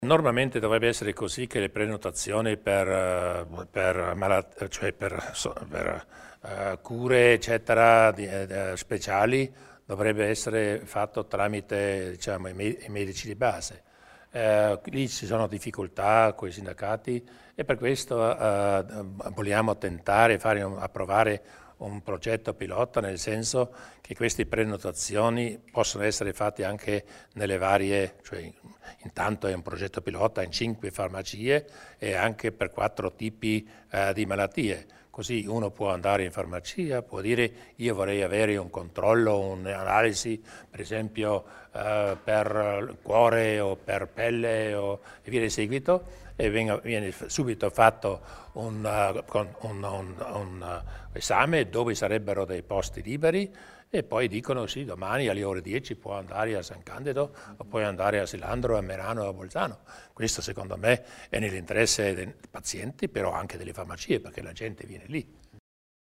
Il Presidente Durnwalder spiega le novità per le prenotazioni di visite specialistiche